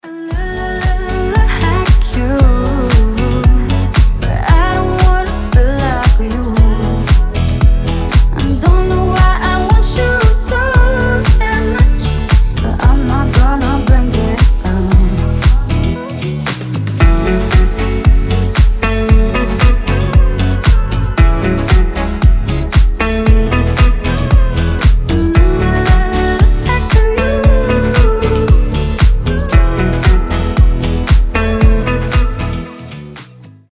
• Качество: 320, Stereo
гитара
deep house
женский голос
приятные
теплые
Chill